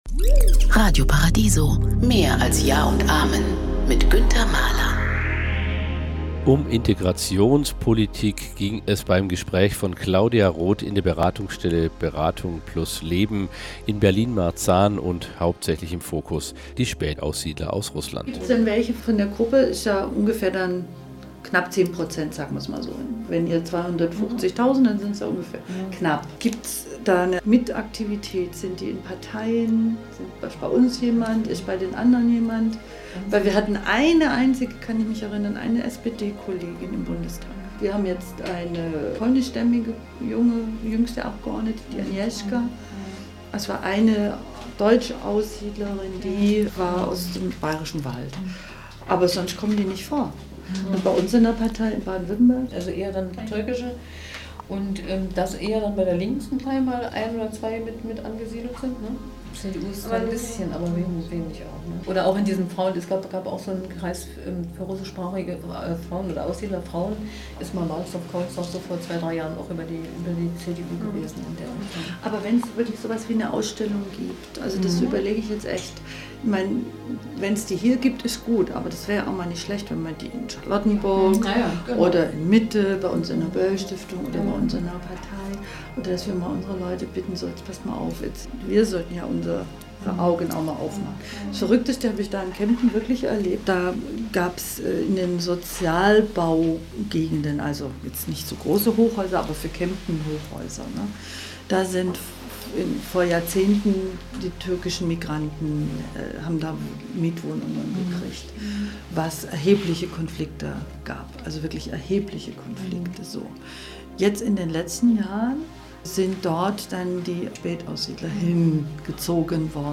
Claudia Roth, ehmalige Bundesvorsitzendene der Partei Bündnis 90/Die Grünen, im Gespräch mit Mitarbeitern der Familienberatung Marzahn von Beratung + Leben.